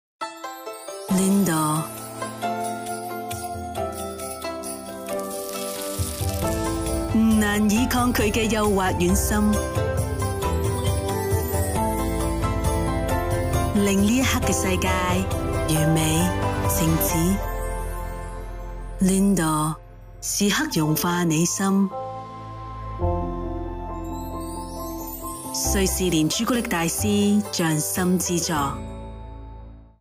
Female
Natural, engaging, authentic, with strong professional delivery
Television Spots
Lindor Ads